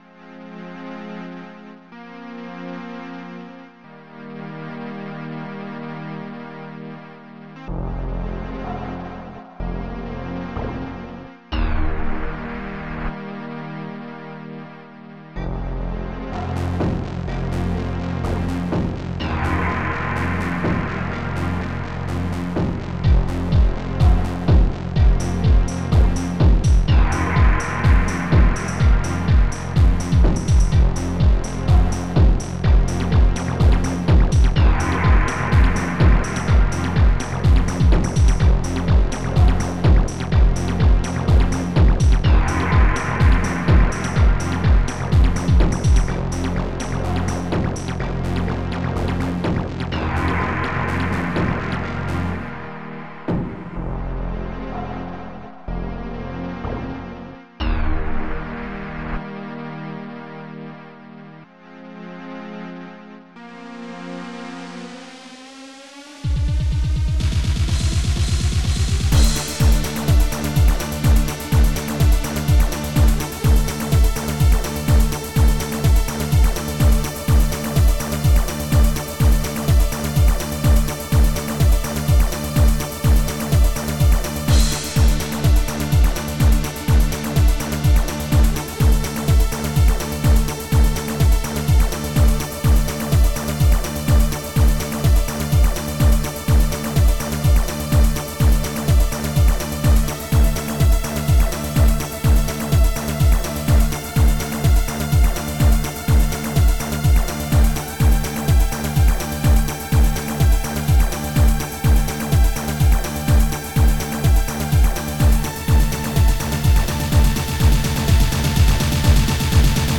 HANDCLAP
HIHAT L